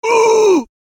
人类 " 男性战斗04
描述：这个战斗尖叫声是为一个mmorpg电脑游戏录制的
Tag: 疼痛 尖叫